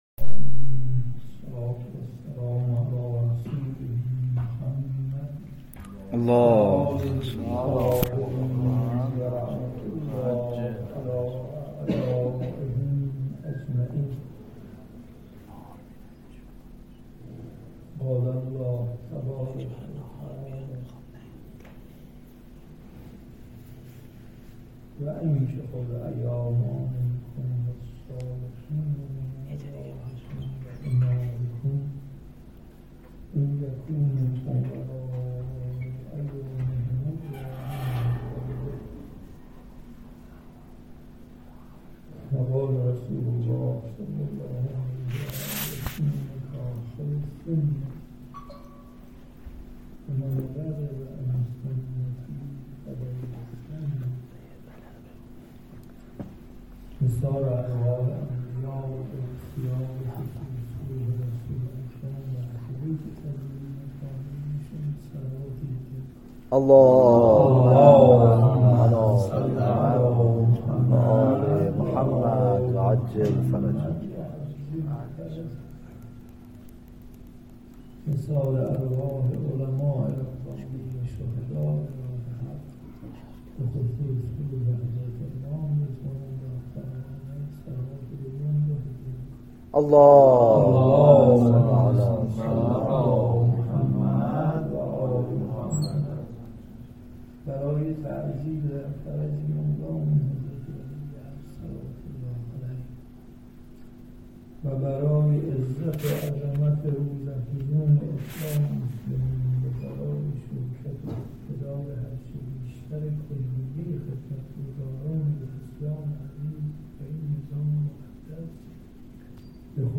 جلسات درس اخلاق سه شنبه ها بعد از نماز جماعت ظهر و عصر در مرکز تخصصی فلسفه اسلامی با محوریت کتاب آداب الصلاه همراه با پاسخ به پرسش های حضار توسط آیت الله فیاضی برگزار می گردد.